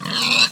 pig_death.ogg